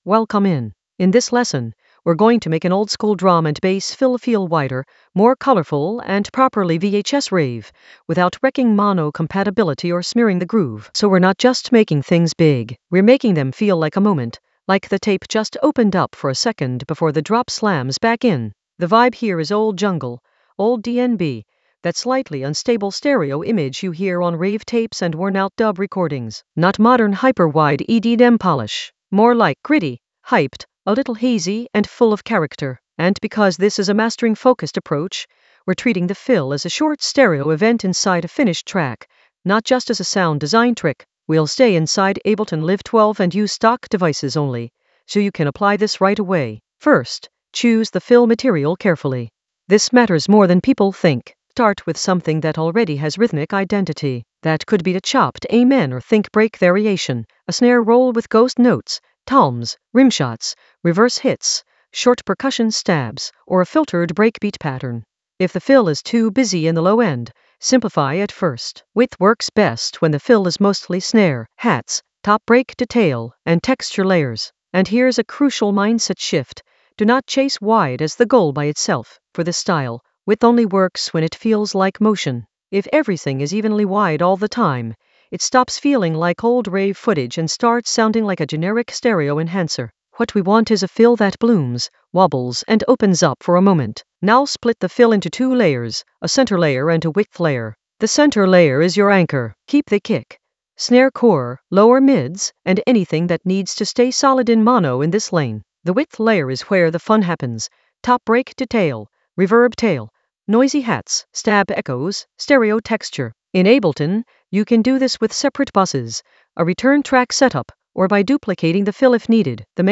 Narrated lesson audio
The voice track includes the tutorial plus extra teacher commentary.
An AI-generated advanced Ableton lesson focused on Widen oldskool DnB fill for VHS-rave color in Ableton Live 12 in the Mastering area of drum and bass production.